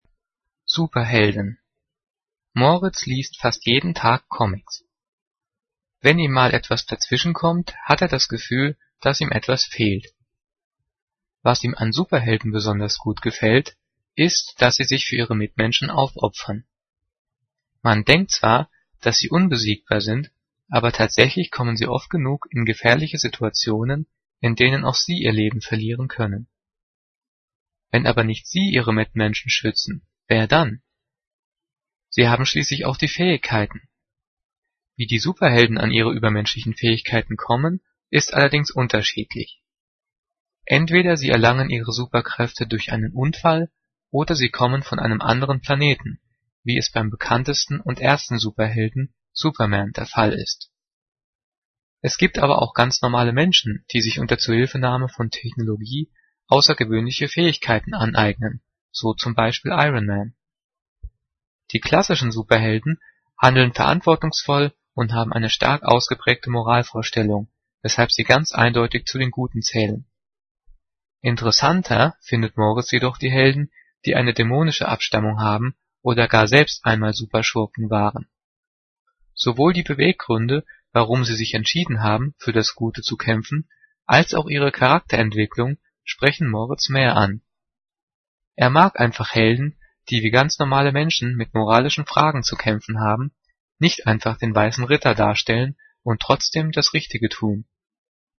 Gelesen:
gelesen-superhelden.mp3